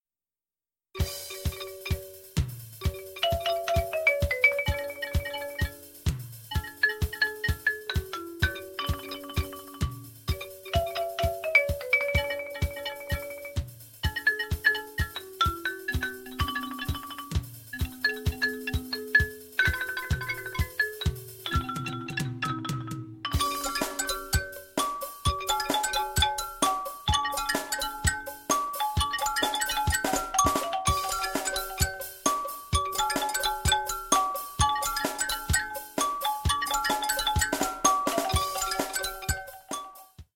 Others sound terrible and even feature some wrong notes.